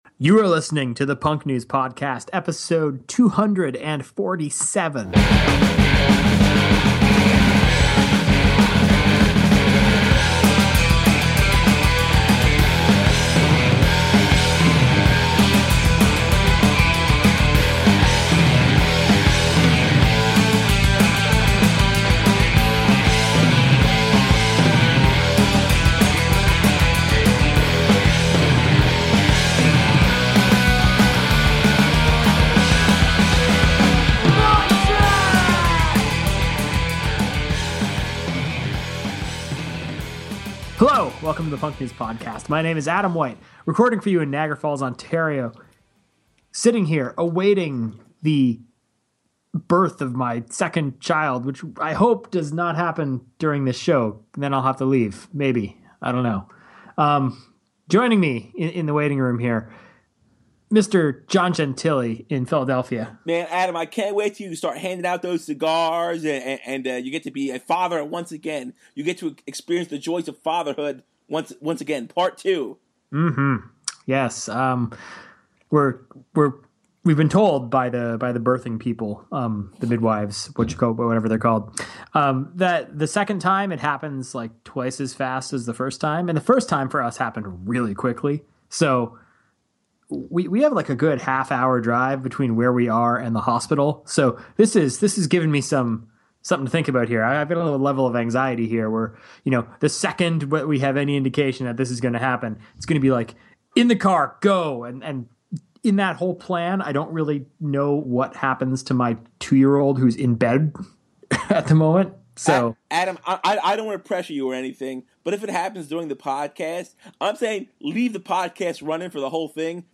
King Morrissey v President Gore. Interview with DEATH.